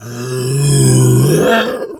pgs/Assets/Audio/Animal_Impersonations/bear_pain_hurt_groan_07.wav at master
bear_pain_hurt_groan_07.wav